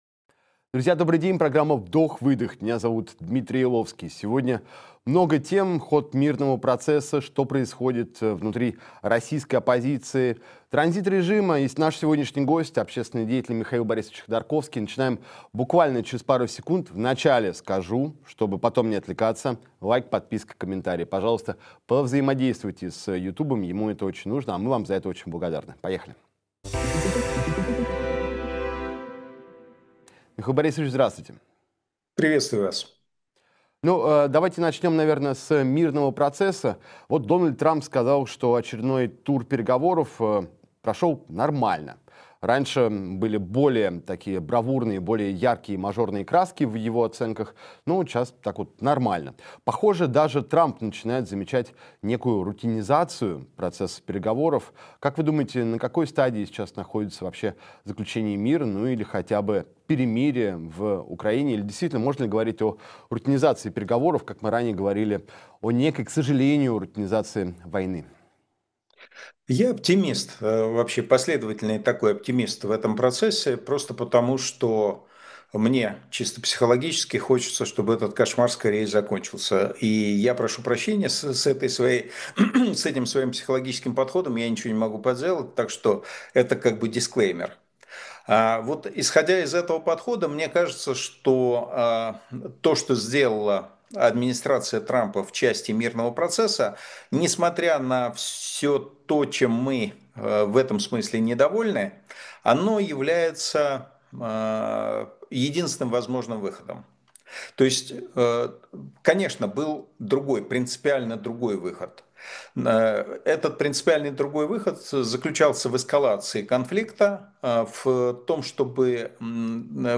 Общественный деятель Михаил Ходорковский — в программе «Вдох-Выдох».